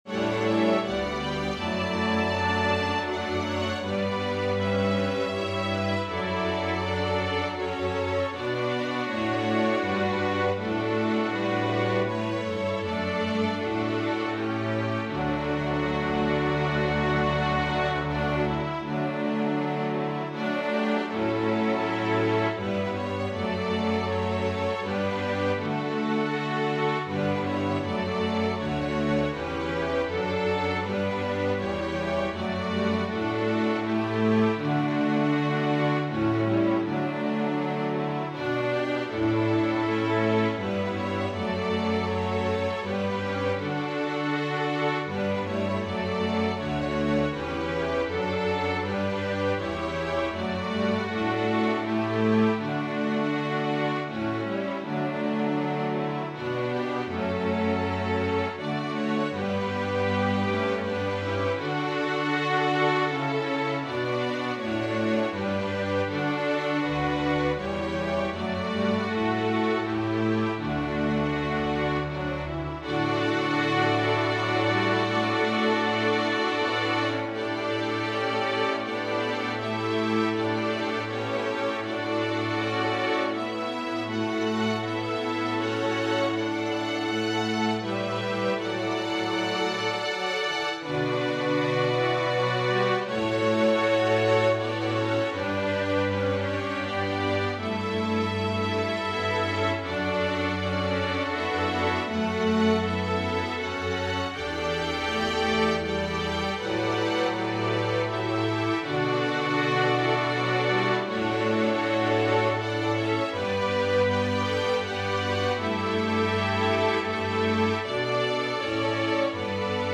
Organ/Organ Accompaniment